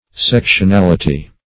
Search Result for " sectionality" : The Collaborative International Dictionary of English v.0.48: Sectionality \Sec"tion*al"i*ty\, n. The state or quality of being sectional; sectionalism.
sectionality.mp3